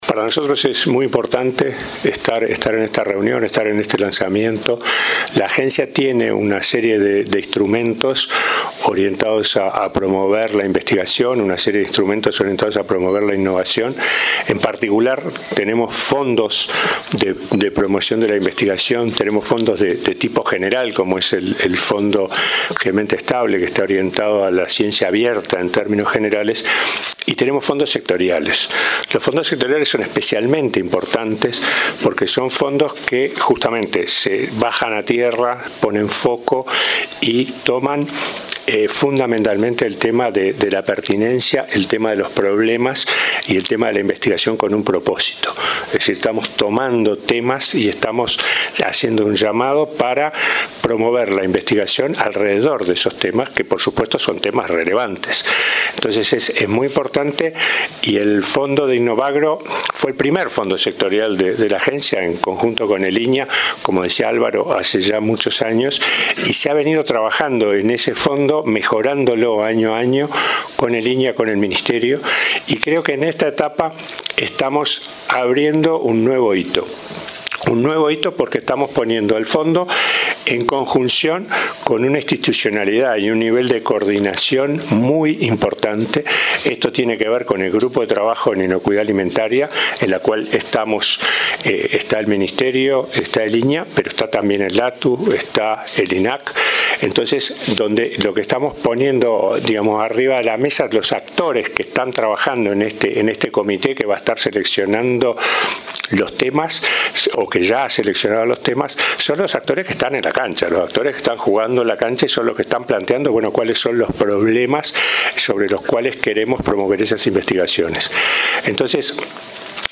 Para agregar más información, escuchamos al Presidente de la Agencia Nacional de Investigación e Innovación (ANII), el Ing. Fernando Brum.